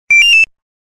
Police Radio Sound Effect
Description: Police radio sound effect. The walkie-talkie beeps. A distinctive high-pitched tone beeps, perfect for games, video clips, and apps emphasizing the presence of police.
Police-radio-sound-effect.mp3